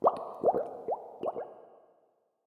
Minecraft Version Minecraft Version 25w18a Latest Release | Latest Snapshot 25w18a / assets / minecraft / sounds / block / bubble_column / upwards_ambient3.ogg Compare With Compare With Latest Release | Latest Snapshot
upwards_ambient3.ogg